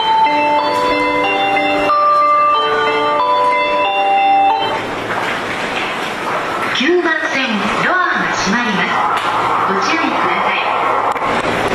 ただ、京成線・新幹線・宇都宮線・高崎線の走行音で発車メロディの収録は困難です。
清流 収録失敗